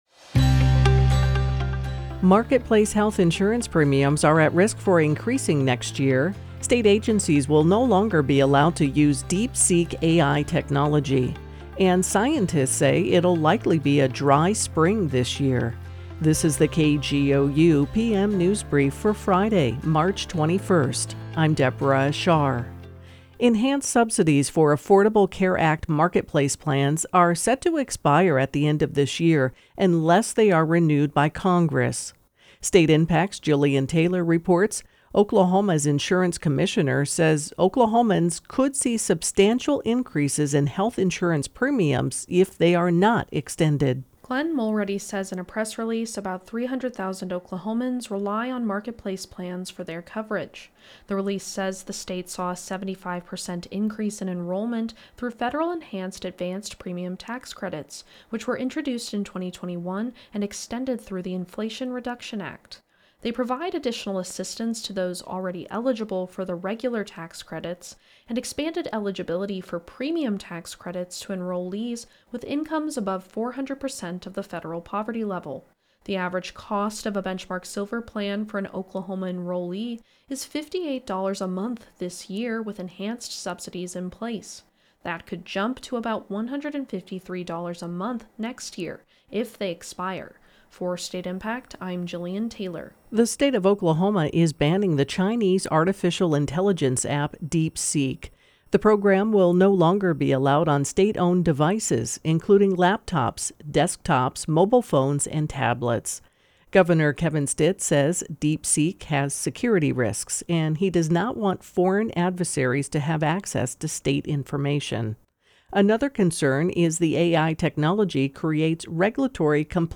Latest Oklahoma news from KGOU - Your NPR Source … continue reading 210 episoder # Daily News # Politics # News # KGOU # KGOU Radio